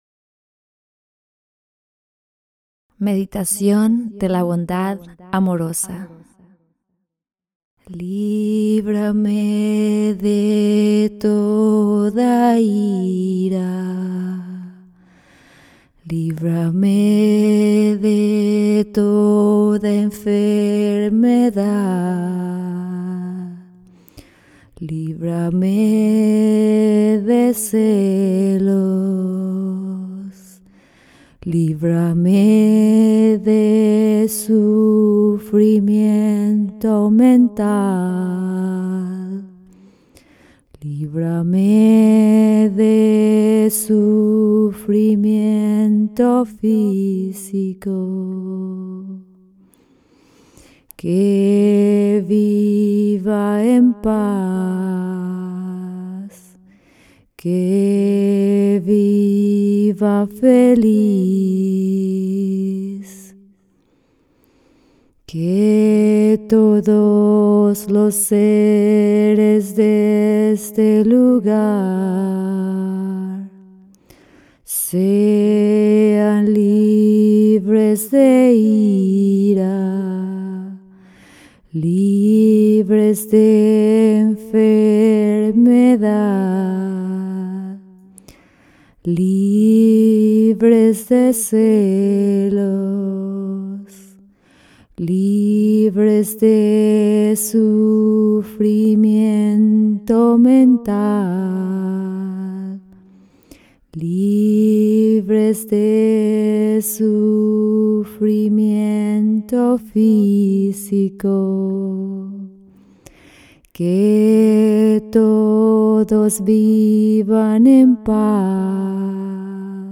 Spanish-Female.mp3